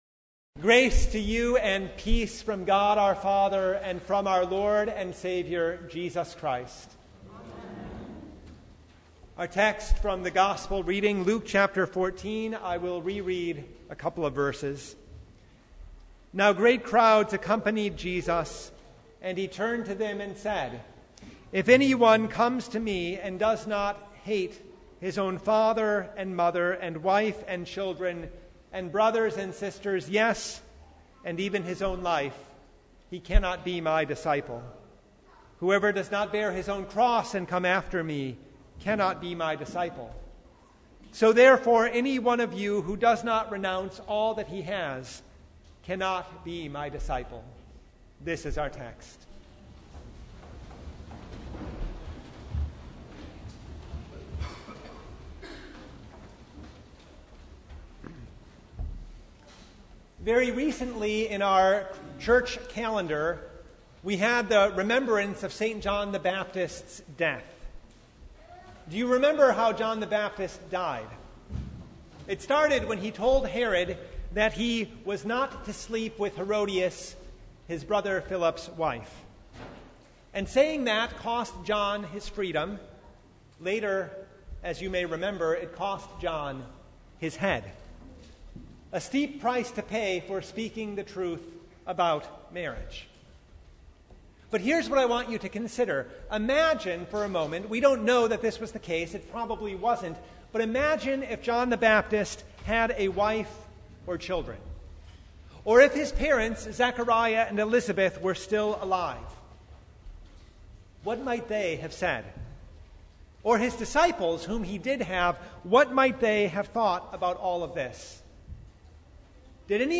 Luke 14:25-35 Service Type: Sunday Jesus tells the crowds the truth about being His disciple